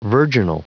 Prononciation du mot virginal en anglais (fichier audio)